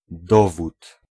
Ääntäminen
US : IPA : [ˈɛ.və.dəns]